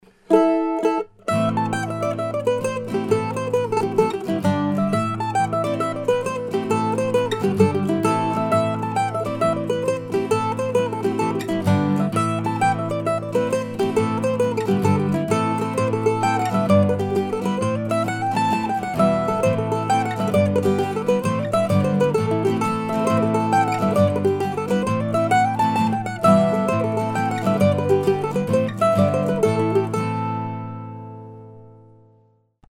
Jigs, pt. 1